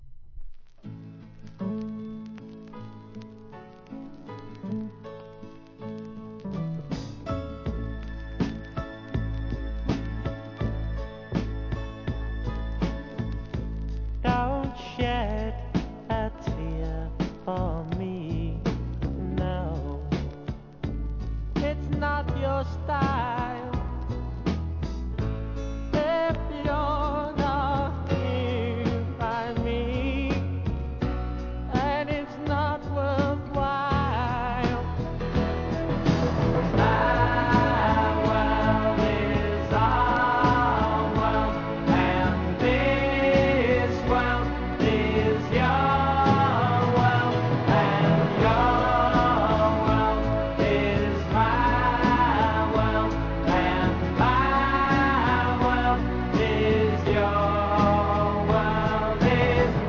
¥ 330 税込 関連カテゴリ SOUL/FUNK/etc...